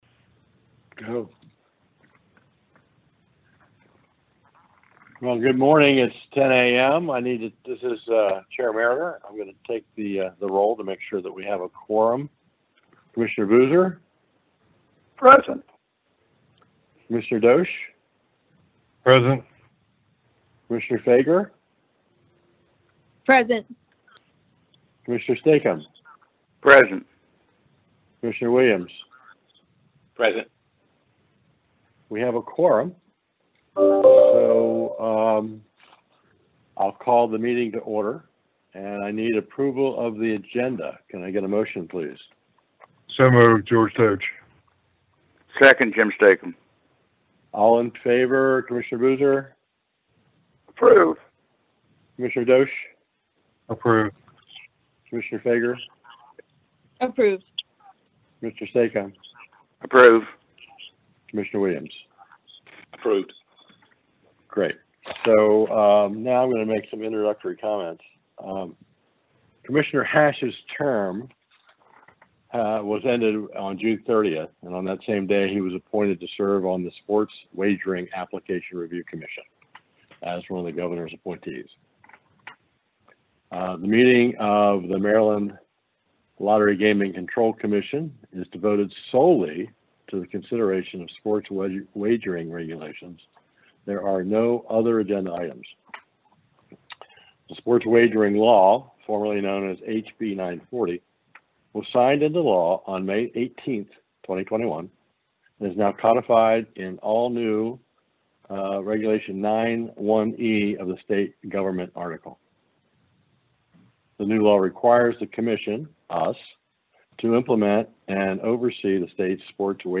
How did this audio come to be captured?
This Commission Meeting was a special exclusive meeting covering sports wagering proposed regulations only on Thursday, July 15, 2021, at 10:00 a.m., conducted by teleconference.